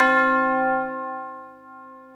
ORCH TUBU1-S.WAV